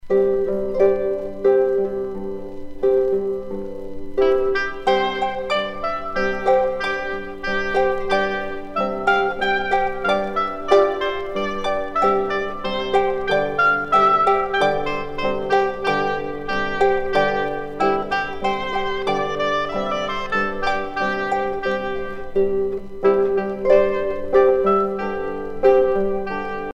La harpe celtique des Îles Hébrides
Pièce musicale éditée